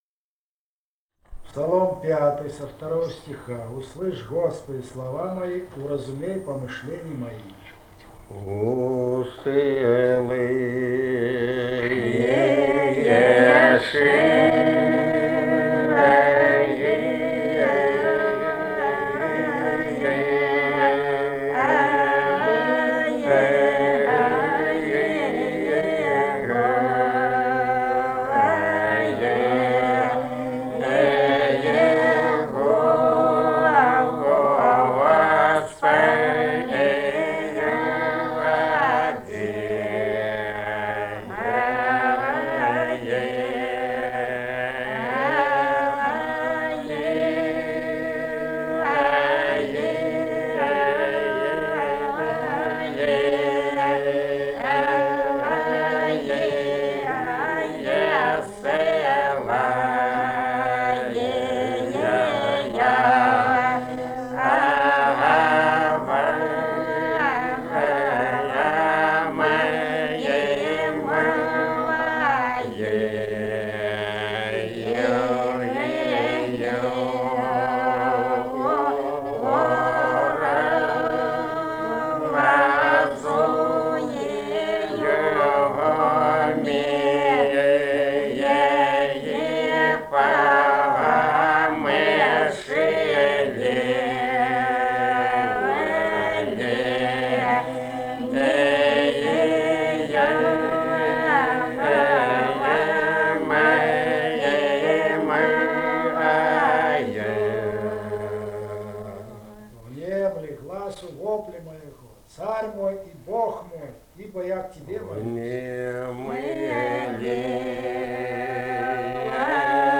полевые материалы
Грузия, г. Тбилиси, 1971 г.